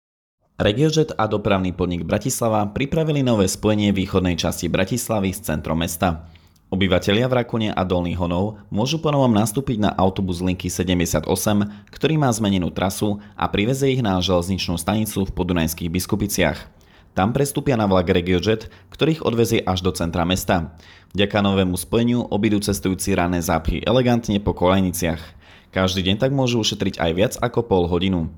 zvukNahrávka pre rozhlasové účely